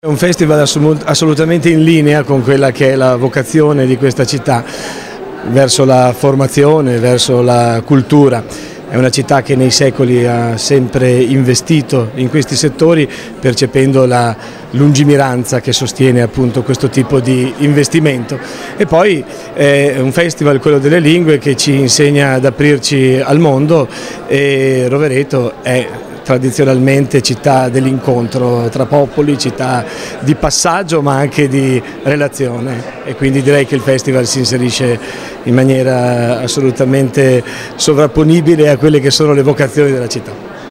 8_marzo_2018_INT_AUDIO_Francesco_Valduga_festival_lingue.mp3